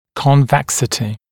[kɔn’veksətɪ][кон’вэксэти]выпуклость